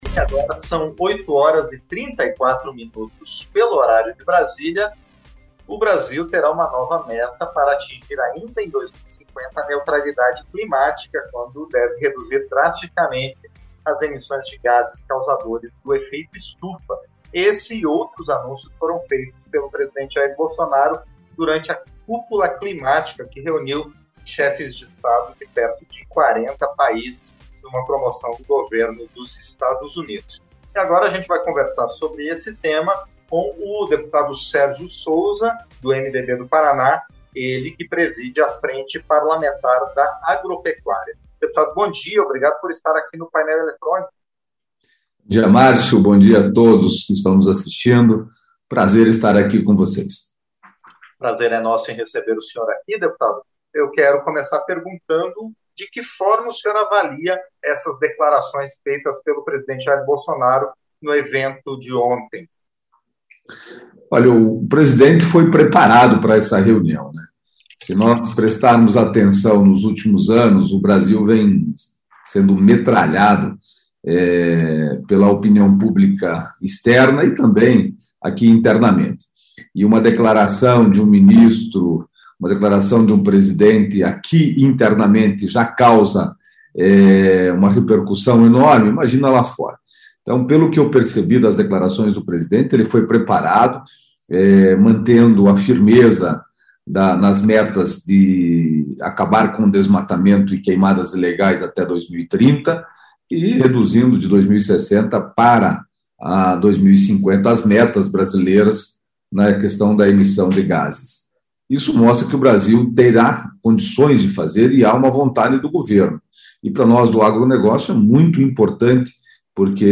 Entrevista - Dep. Sérgio Souza (PMDB-PR)